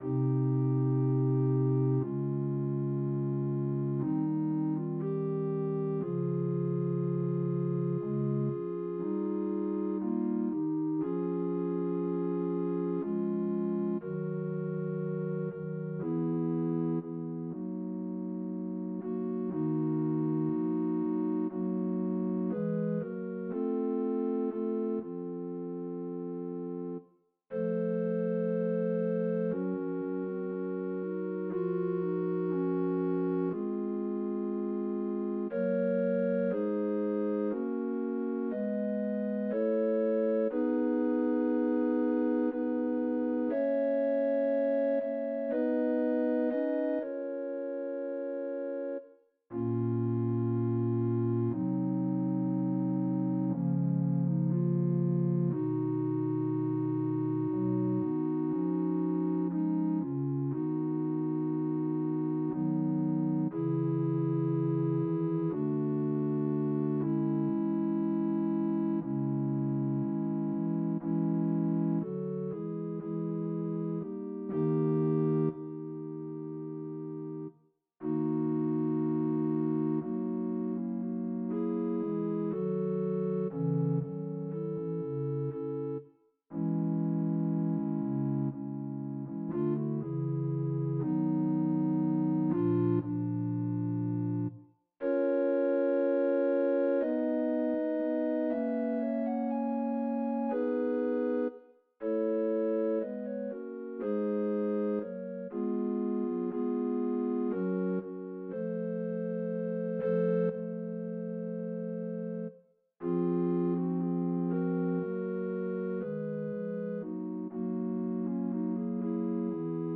Tantum Ergo, with quite simple lines. Because the vocals in Dorico are pretty bad I made the music sound like recorders without vibrato.